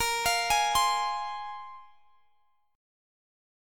Bb7sus2 Chord
Listen to Bb7sus2 strummed